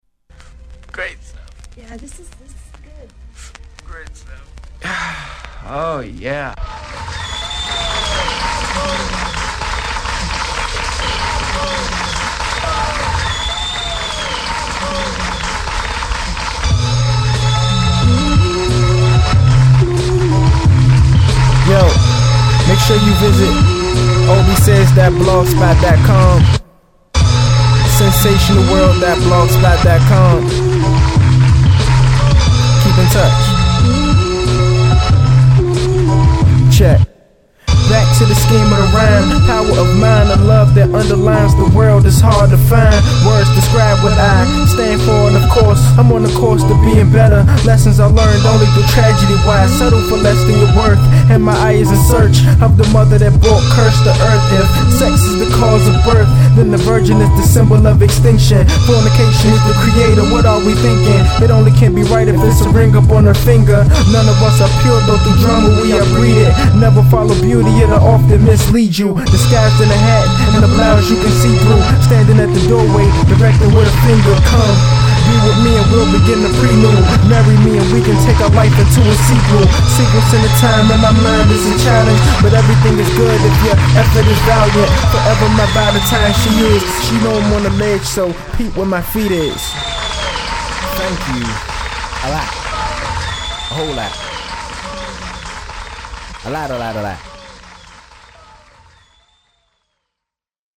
Underground Hip Hop